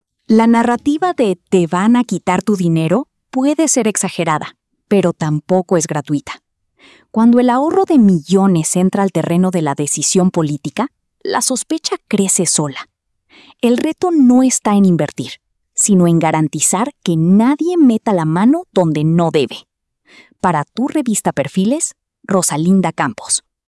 🎙 COMENTARIO EDITORIAL 🎤